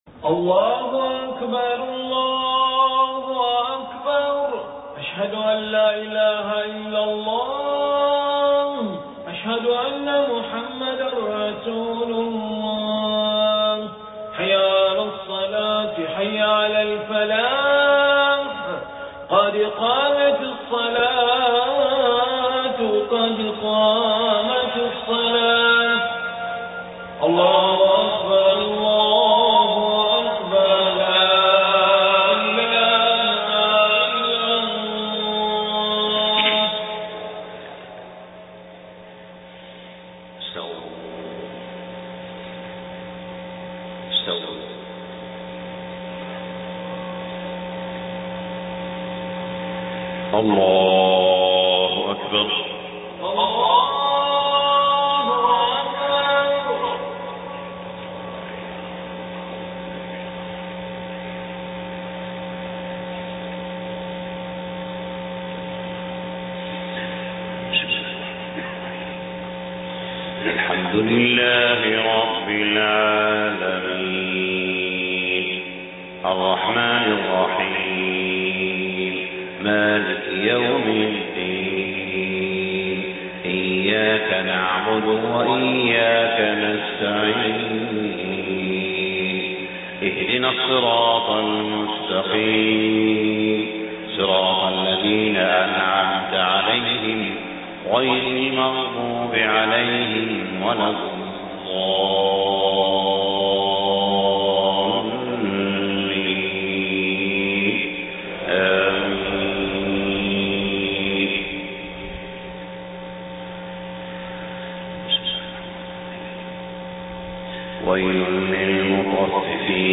صلاة الفجر 19 محرم 1430هـ سورتي المطففين والبروج > 1430 🕋 > الفروض - تلاوات الحرمين